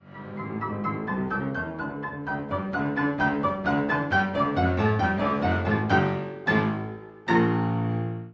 fortepiano